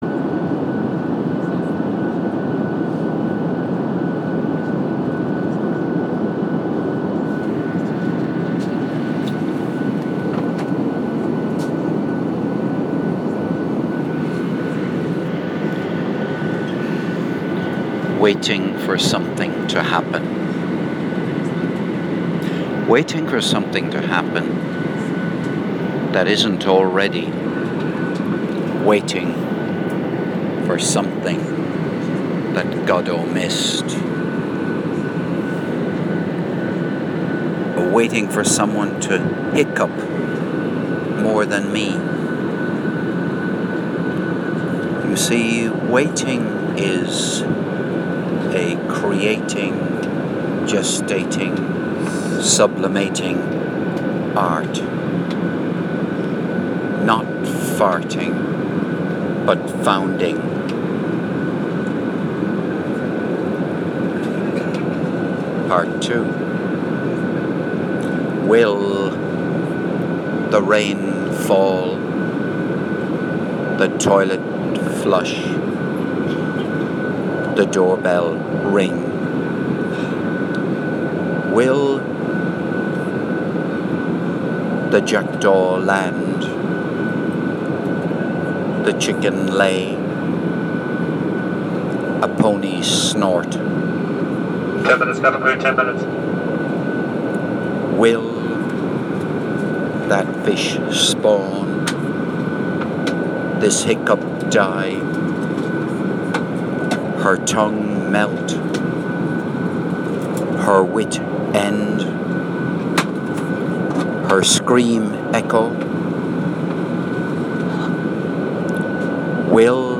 Waiting for something to happen (composed & voiced on RyanAir)